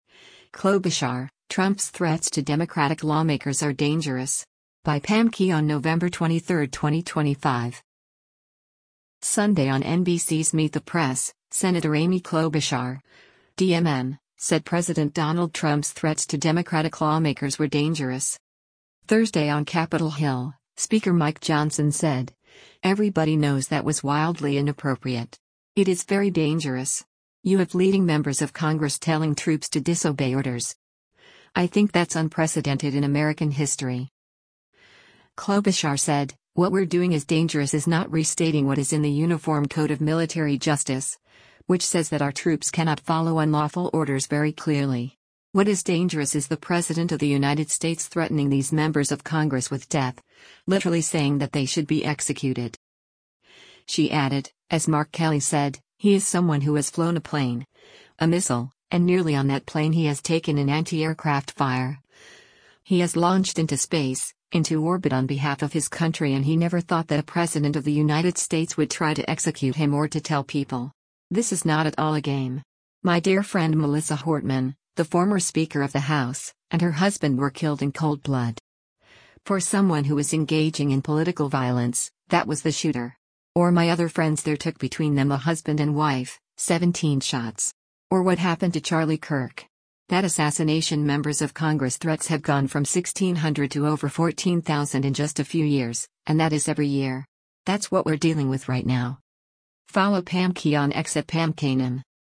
Sunday on NBC’s “Meet the Press,” Sen. Amy Klobuchar (D-MN) said President Donald Trump’s threats to Democratic lawmakers were “dangerous.”